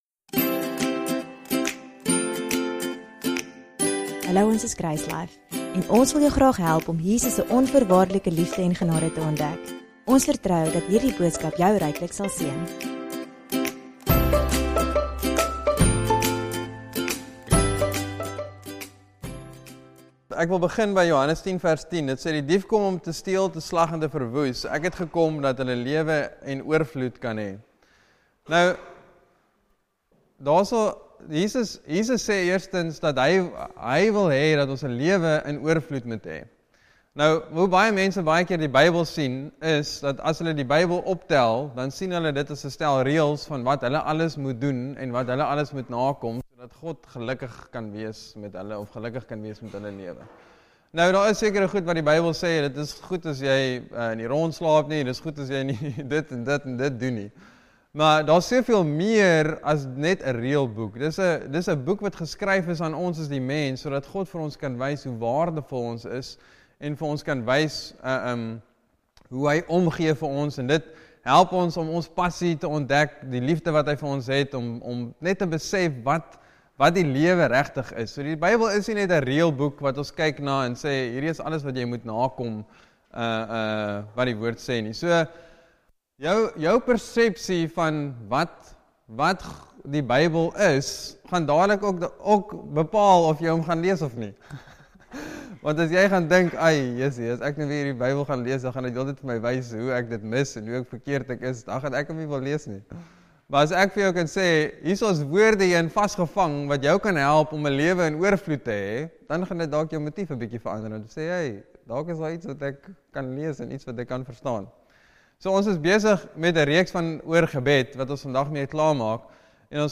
DOWNLOAD READ MORE Sermon Test Category